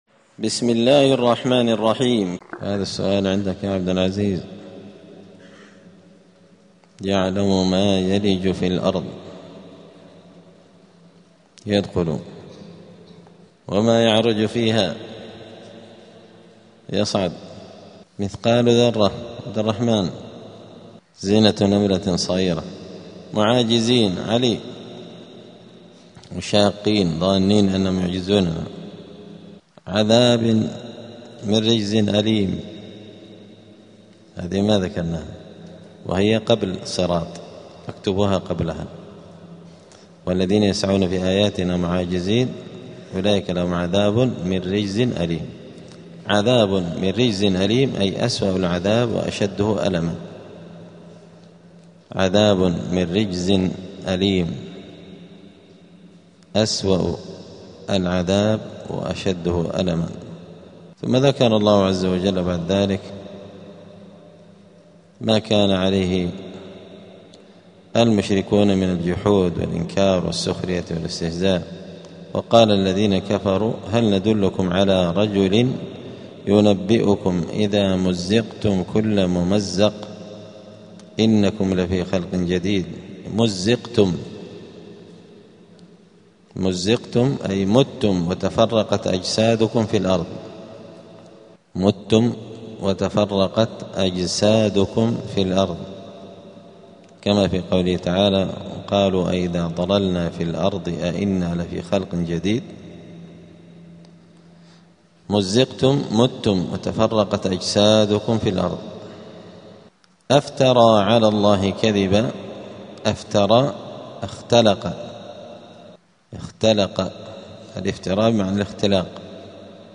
الأثنين 23 رجب 1447 هــــ | الدروس، دروس القران وعلومة، زبدة الأقوال في غريب كلام المتعال | شارك بتعليقك | 3 المشاهدات